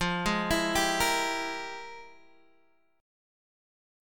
FmM11 chord